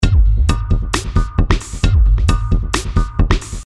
Til sammenligning er der en lydfil af en tromme med det tilsvarende lydspektrum.
[Normal - GIF: 6k]   Billedtekst: PR Foto 02 viser "frekvens spektret" for en tromme.